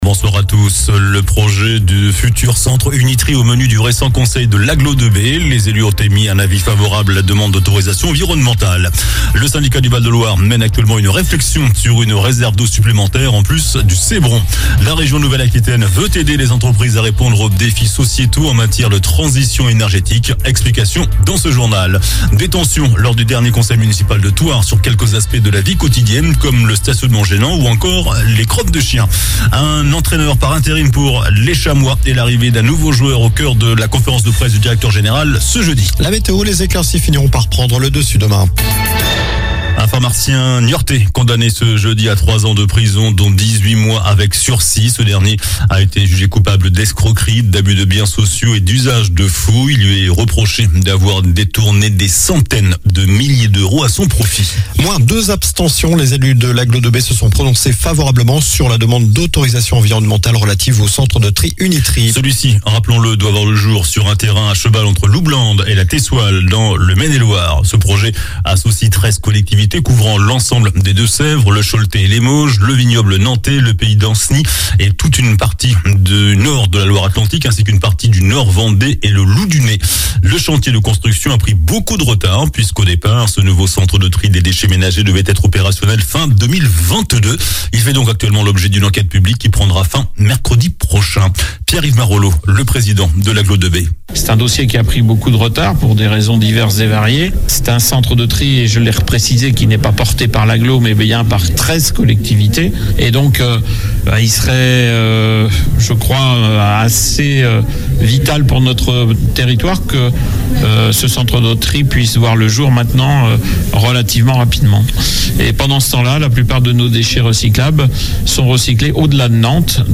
JOURNAL DU JEUDI 02 FEVRIER ( SOIR )